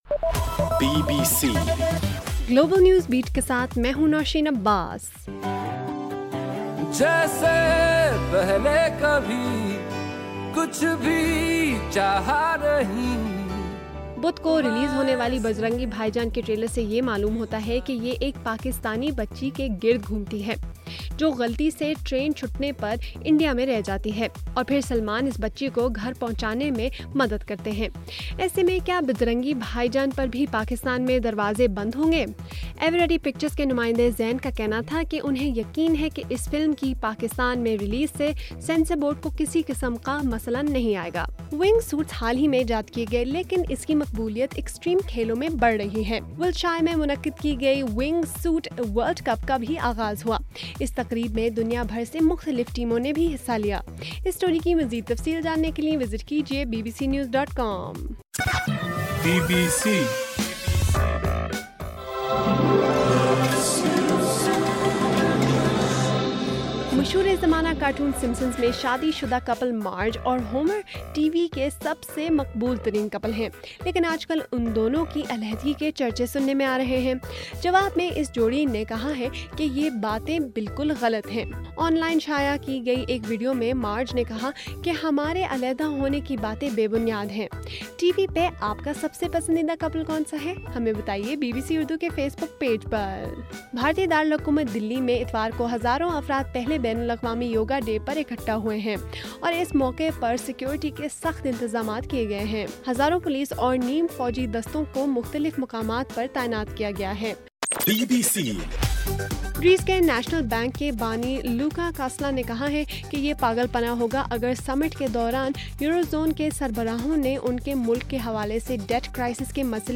جون 21: رات 9 بجے کا گلوبل نیوز بیٹ بُلیٹن